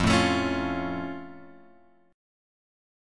F+ chord {1 0 x 2 2 1} chord
F-Augmented-F-1,0,x,2,2,1.m4a